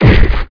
strike2.wav